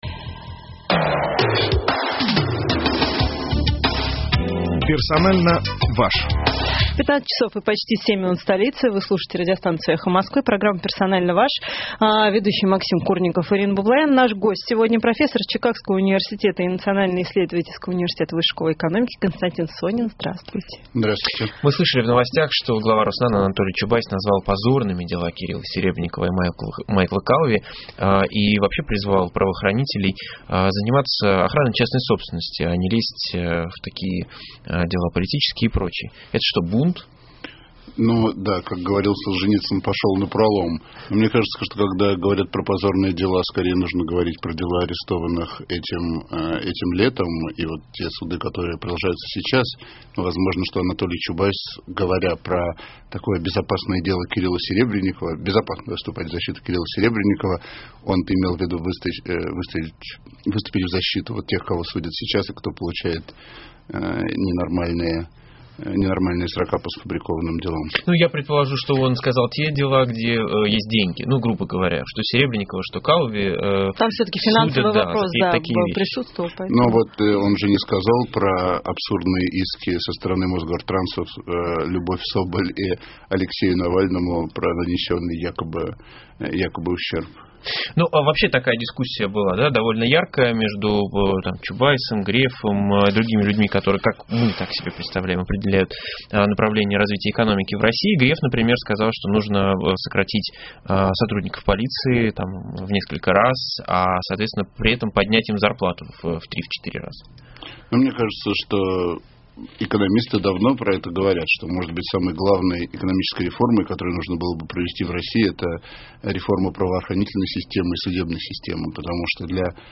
Наш гость сегодня – профессор Чикагского университета и Национального исследовательского университета Высшей школы экономики Константин Сонин, здравствуйте.